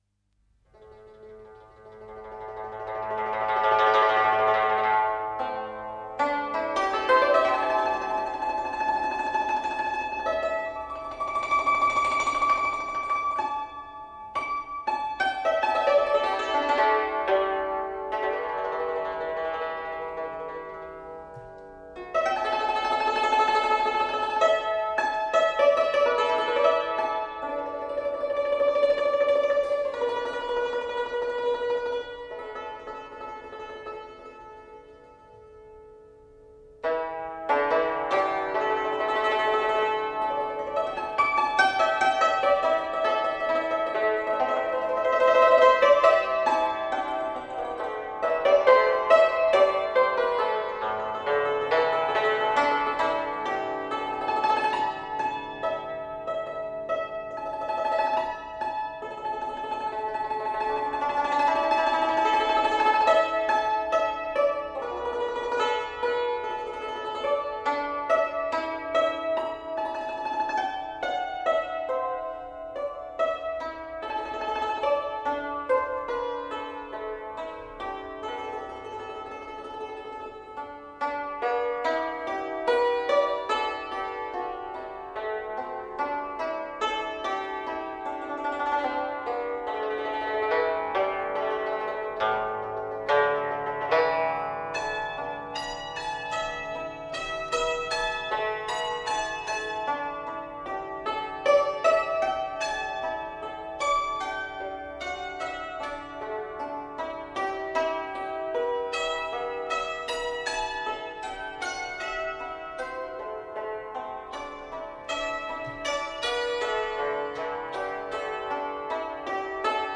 扬琴
扬琴独奏